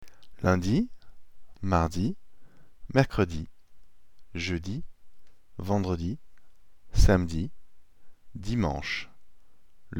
پادکست صوتی ایام هفته در فرانسه :
• روز شنبه با نوشتار Samedi می باشد که تلفظ آن sahm-DEE می باشد
• روز یکشنبه با نوشتار Dimanche می باشد که تلفظ آن dee-MAHNSH میباشد
• روز دوشنبه با نوشتار Lundi می باشد که دارای تلفظ luhn-DEE است
• روز چهارشنبه با نوشتار Mercredi می باشد که تلفظ آن mehr-kruh-DEE می باشد
• روز پنجشنبه با نوشتار Jeudi می باشد که تلفظ آن juh-DEE می باشد
• روز جمعه با نوشتار Vendredi می باشد که تلفظ آن vahn-druh-DEE می باشد
days-of-the-week-french.mp3